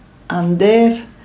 Roh-sursilvan-Andeer.ogg.mp3